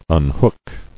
[un·hook]